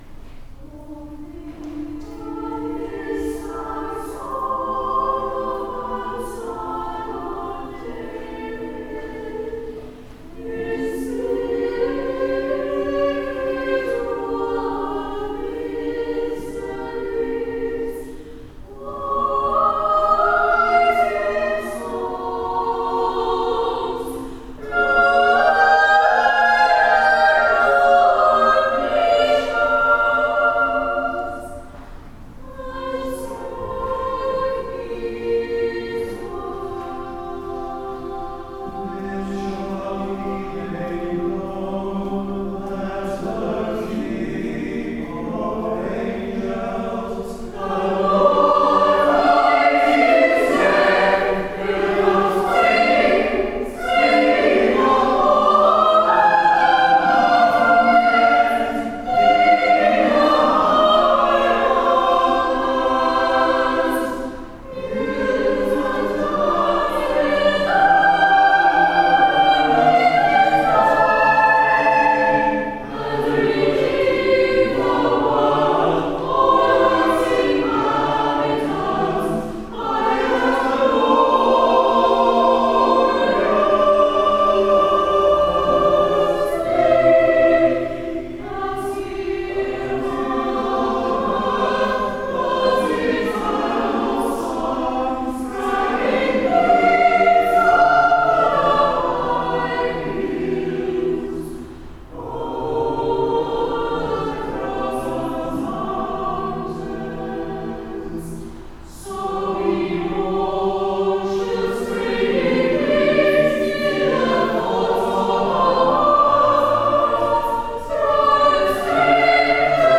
Number of voices: 4vv Voicing: SATB, with div Genre: Sacred, Anthem
Language: English Instruments: A cappella